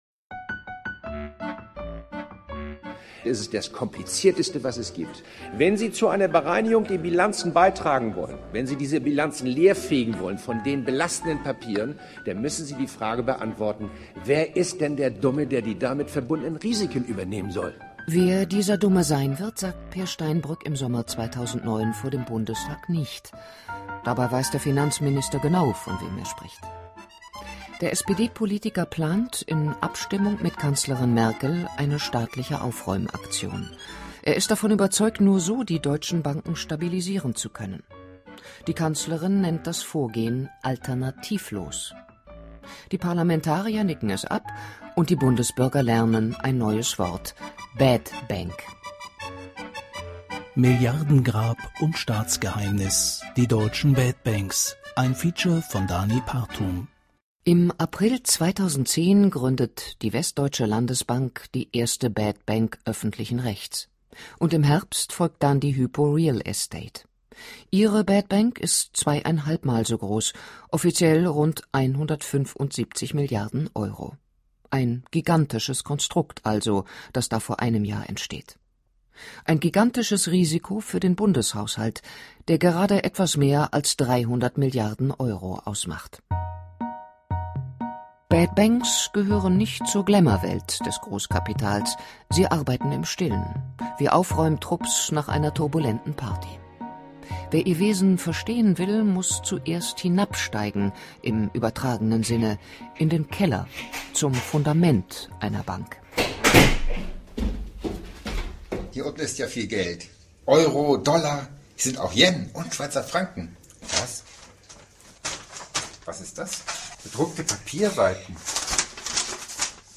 Das 30minütige Radio-Feature können Sie hier nachhören.